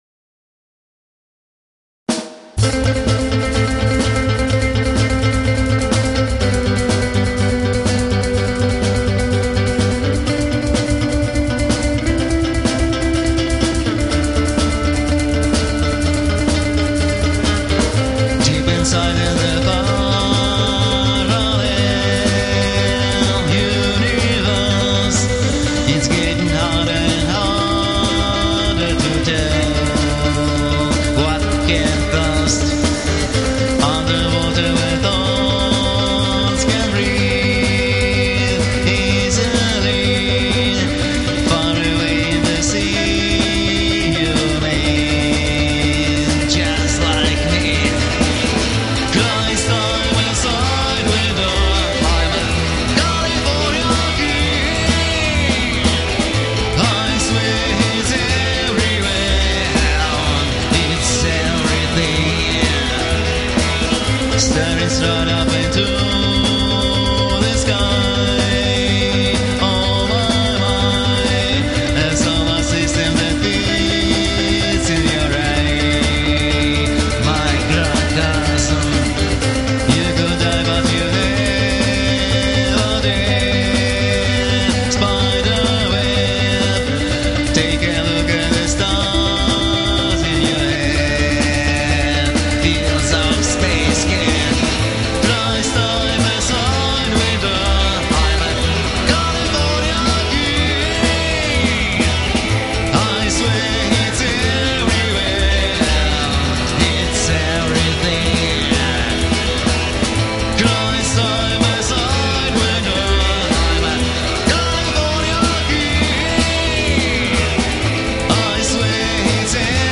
контрабас, вокал
Известные рокабильные хиты